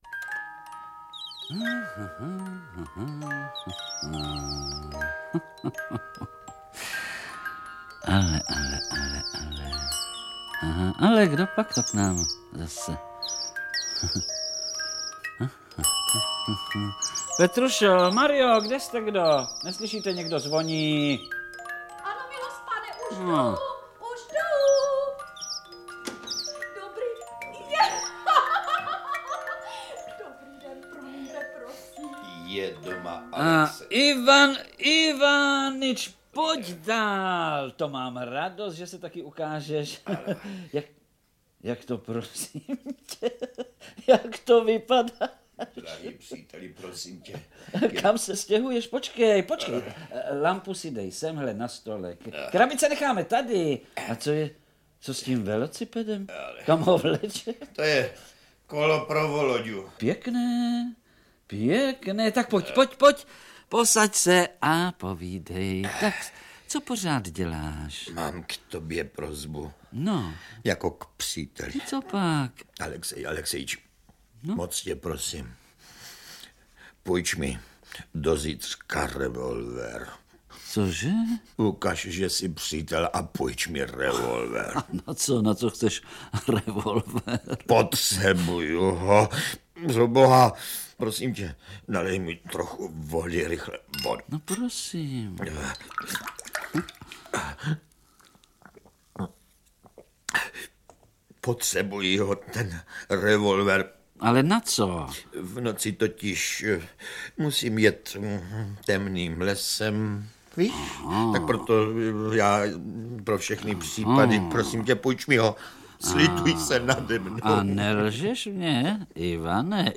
Interpreti:  Jaroslava Adamová, Vlastimil Brodský, Rudolf Hrušínský, Ladislav Pešek, Josef Somr